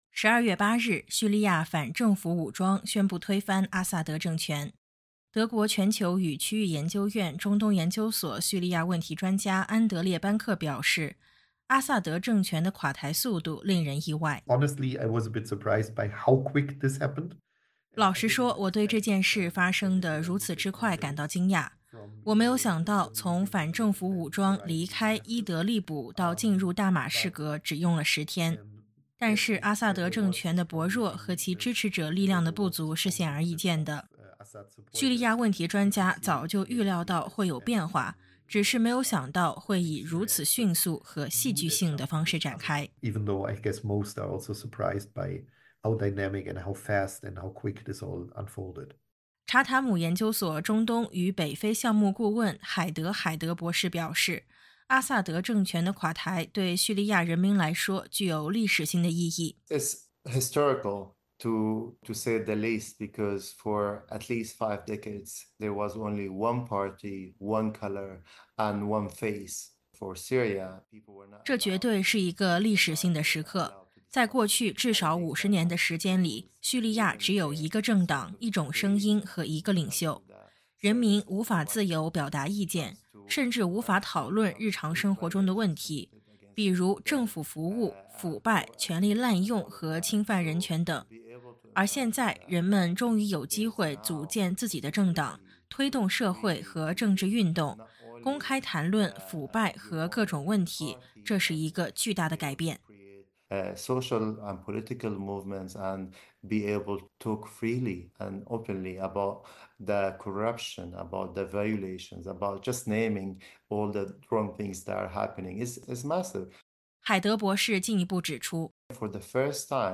点击音频，收听综合报道。